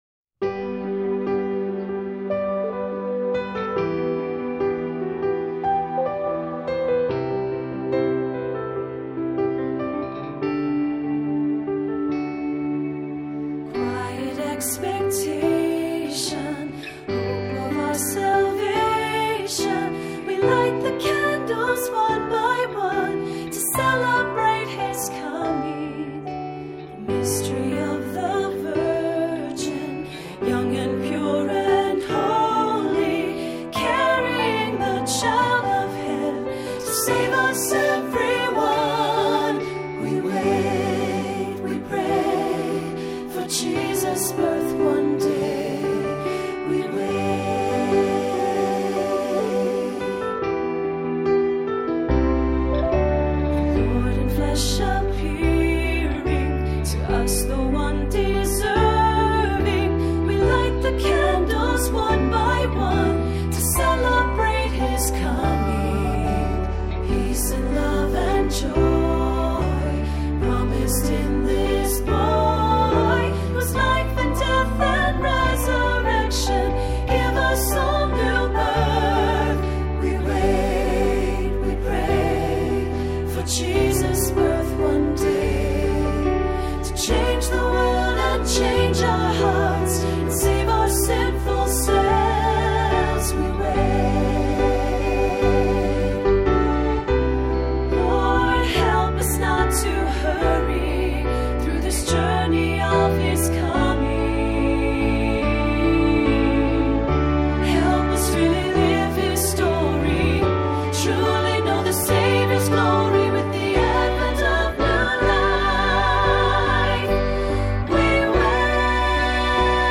Voicing: SATB, assembly,Soloist or Soloists